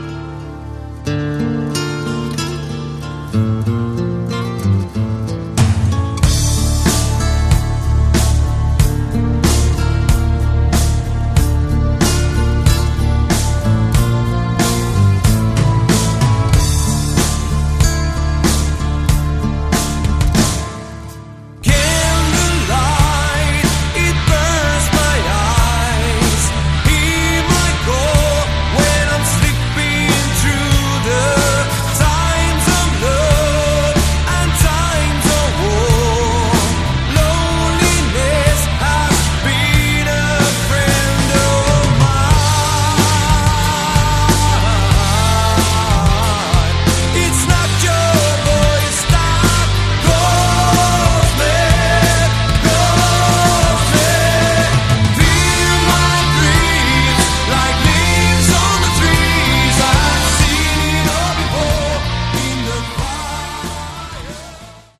Category: Melodic Hard Rock
Guitar, Vocals
Drums
Bass
Keyboards, Vocals